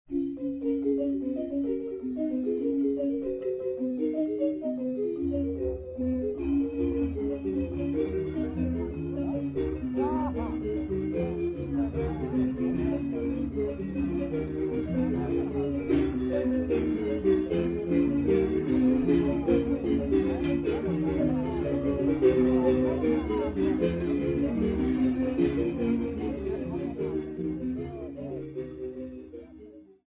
Folk music -- South Africa
Xylophone (Musical instruments)
Drum (Musical instruments)
Rattle (Musical instruments)
Sub-Saharan African music
field recordings
Traditional call and response vocal work
Cassette tape